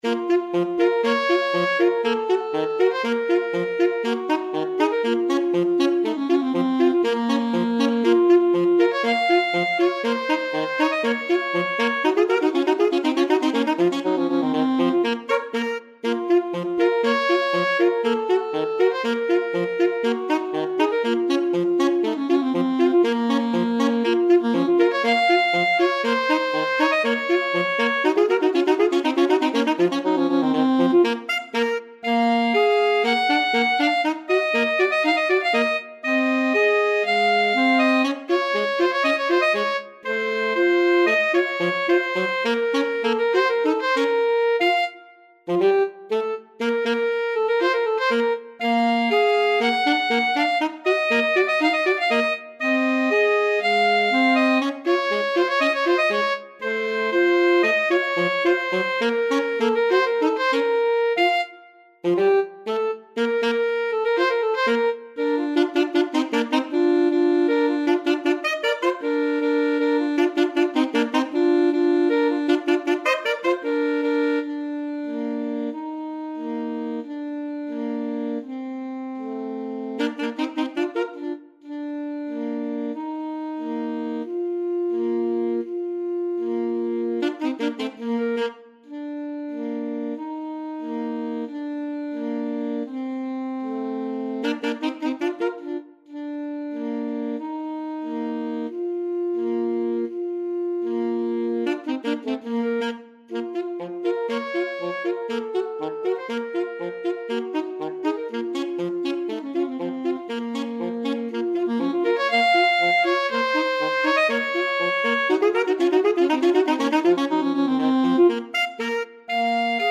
Free Sheet music for Alto Saxophone Duet
Alto Saxophone 1Alto Saxophone 2
This piece comes from a set of 21 lively dance tunes based mostly on Hungarian themes.
Bb minor (Sounding Pitch) F minor (French Horn in F) (View more Bb minor Music for Alto Saxophone Duet )
2/4 (View more 2/4 Music)
Allegro = 120 (View more music marked Allegro)
Classical (View more Classical Alto Saxophone Duet Music)